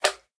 slime_small1.ogg